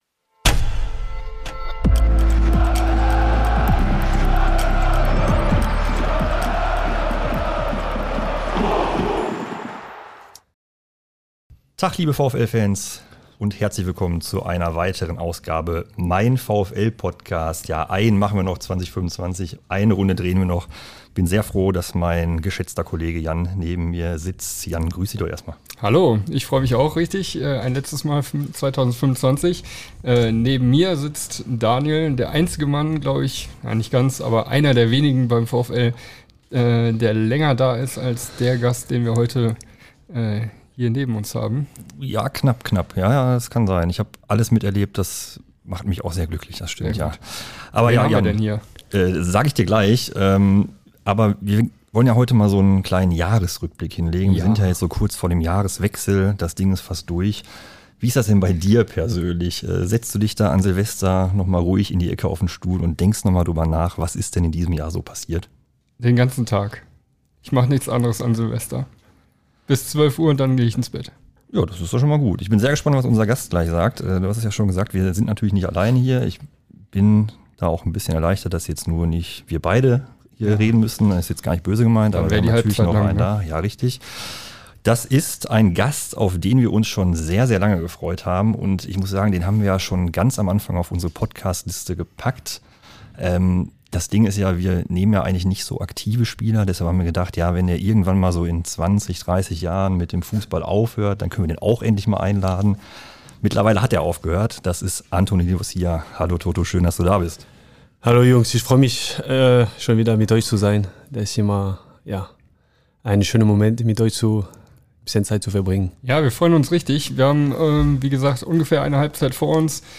In der finalen Folge wollen wir noch einmal auf das blau-weiße Jahr zurückschauen. Und dafür haben wir den perfekten Gast gefunden.